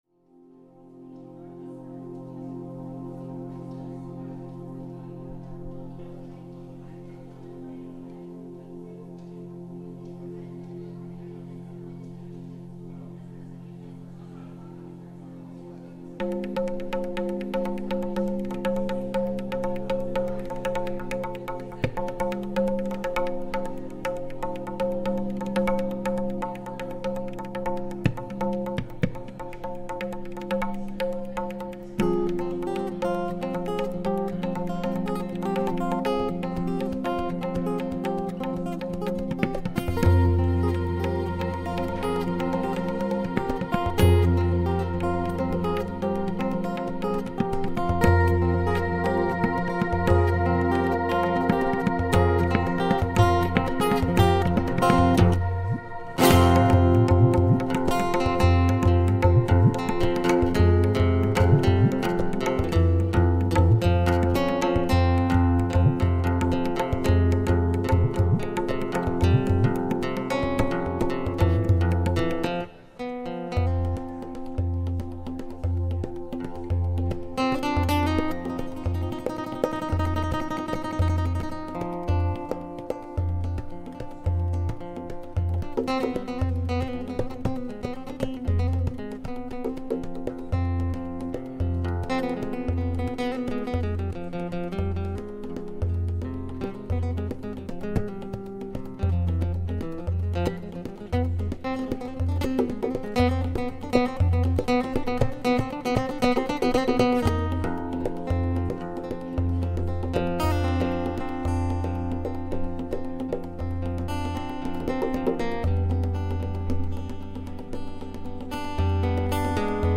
Live at 51 Main, Middlebury VT 11/7/08